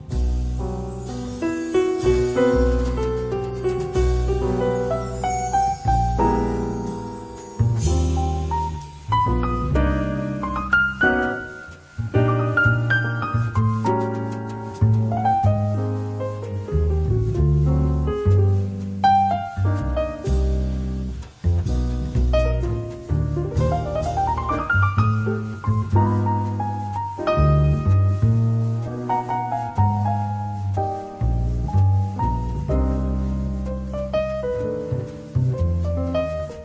The Best In British Jazz
Recorded Red Gables Studios, London October 13th 2003